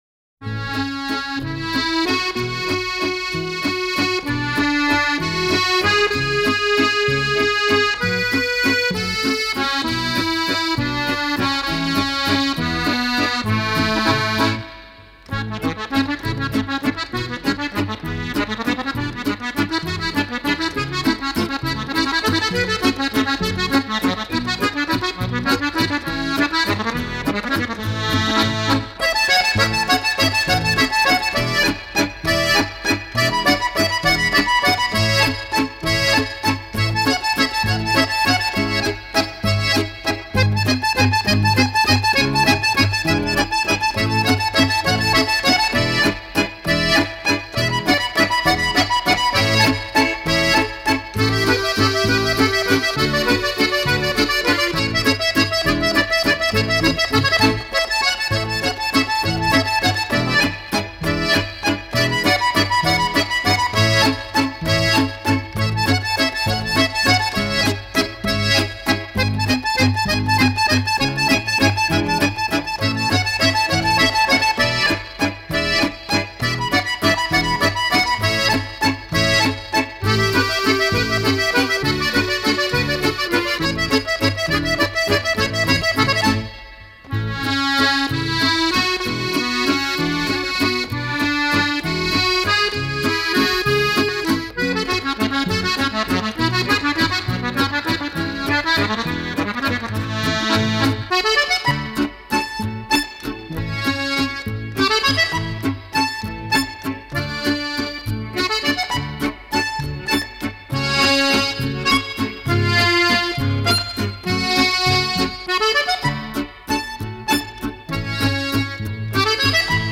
Celebre Valzer